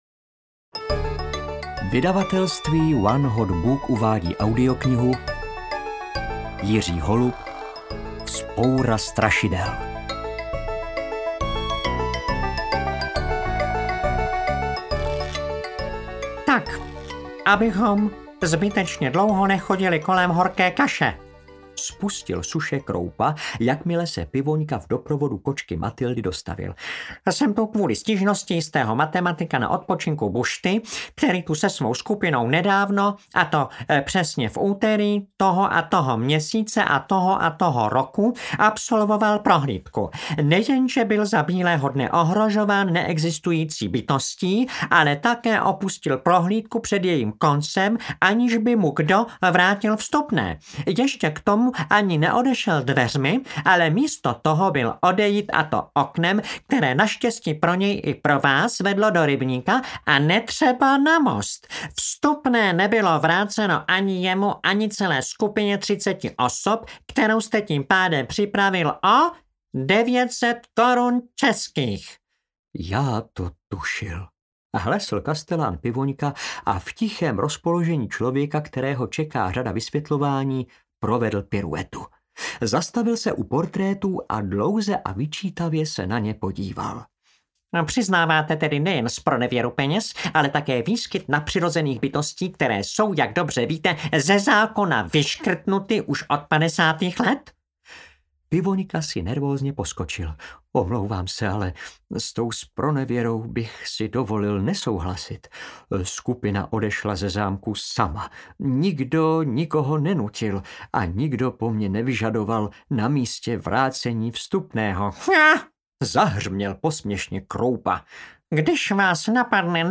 Vzpoura strašidel audiokniha
Ukázka z knihy
• InterpretJaroslav Plesl